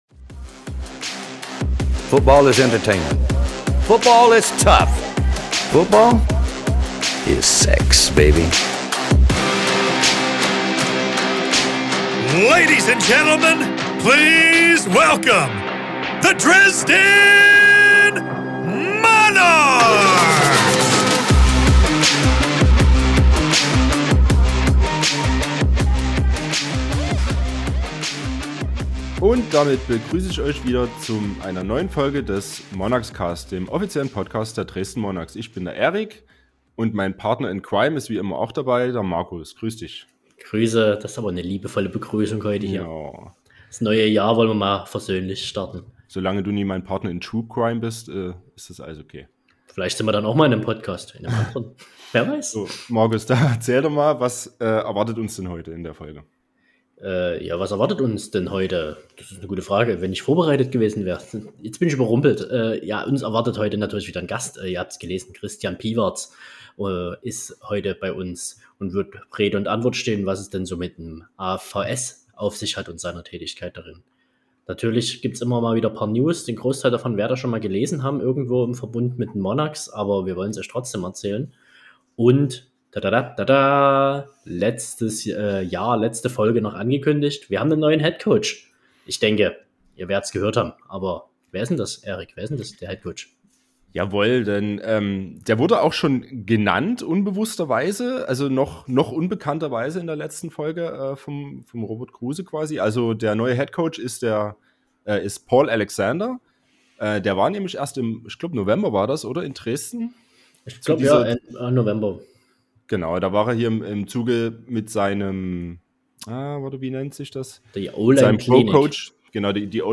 Außerdem wird immer ein interessanter Gast in einem Interview Rede und Antwort stehen.